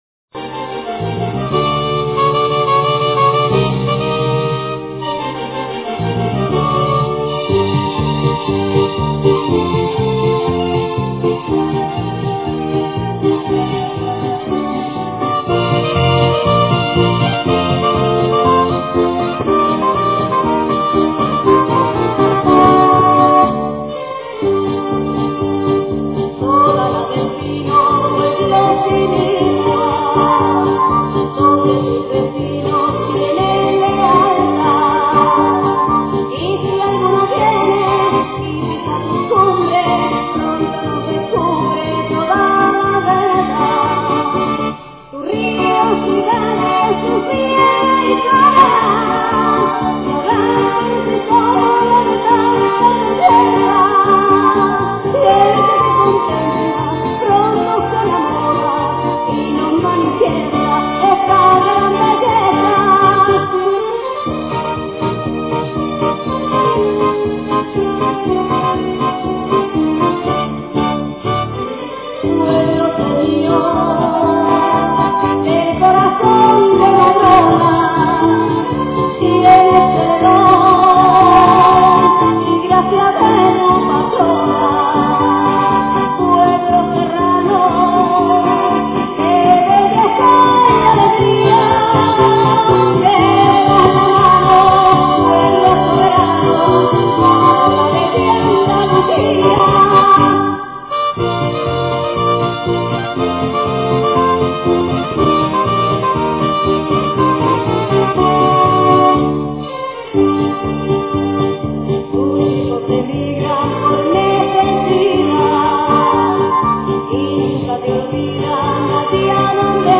pasodoblesp.mp3